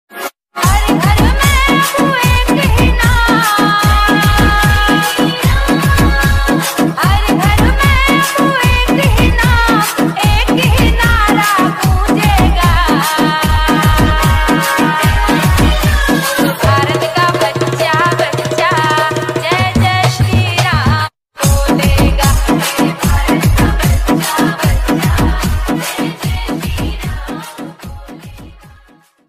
Devotional Ringtones